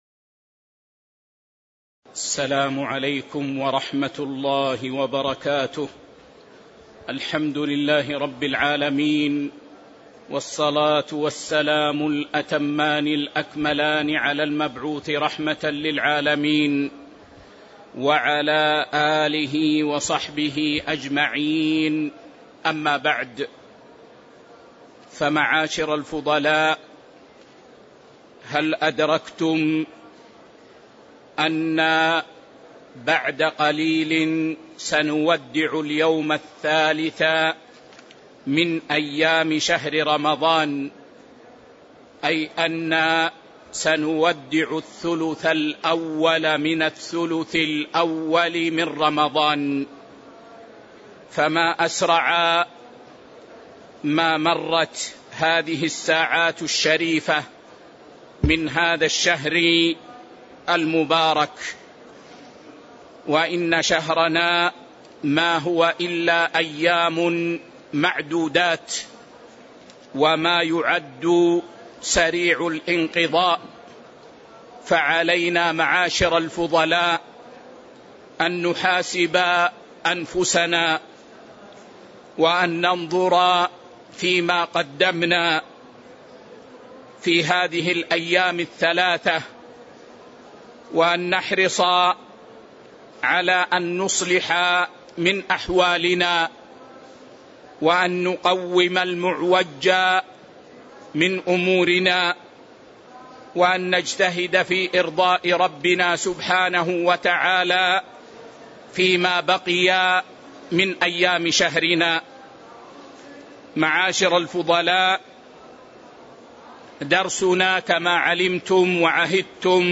شرح صحيح الترغيب والترهيب 1 الدرس 89 كتاب الصيام 5 باب الترغيب في الصوم مطلقًا، وباب الترغيب في صيام رمضان احتساباً